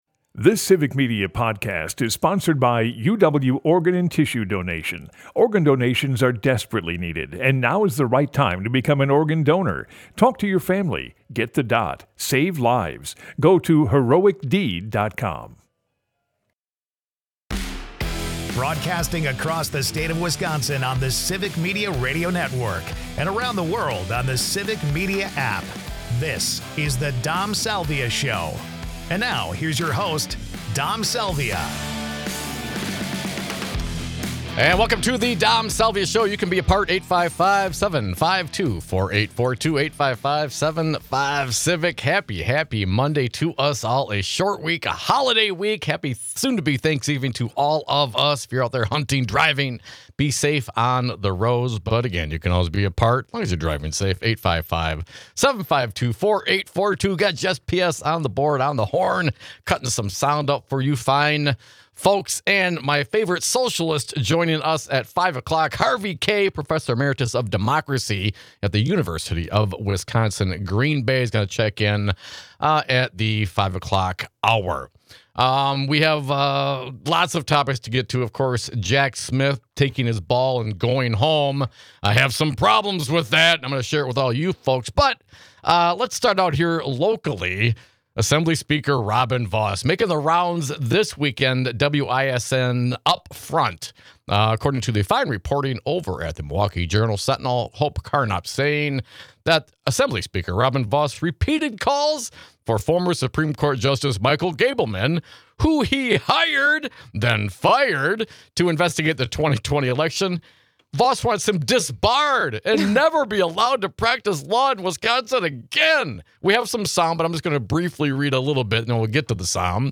Their conversation covered Vos' plans for the next budget and how little he plans to give to education, Vos' hopes that Michael Gableman is disbarred and prevented from practicing law ever again, and how he turned into such a die-hard Trump supporter. In his mile a minute ramble, Vos claims that the enormous spate of school referenda on ballots across the state prove a lack of need for education funding reform.
for news (some), humor (more), and great conversation (always)!